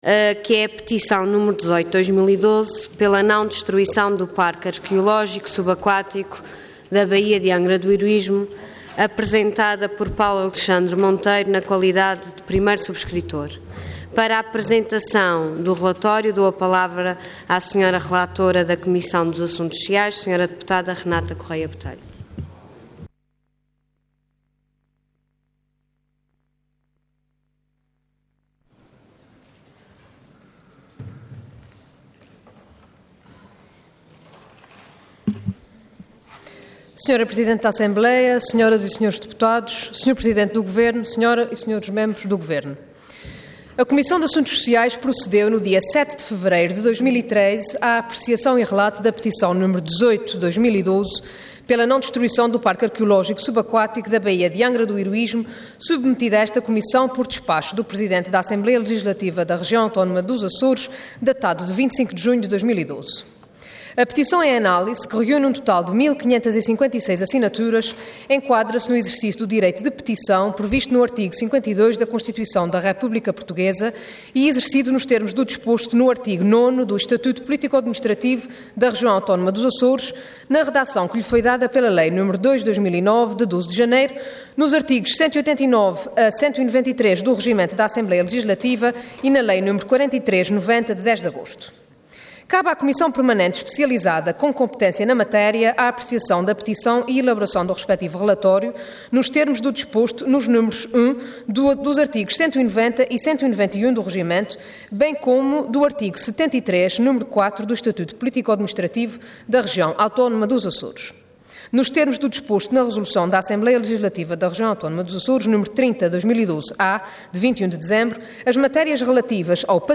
Intervenção Petição Orador Renata Correia Botelho Cargo Deputada Entidade PS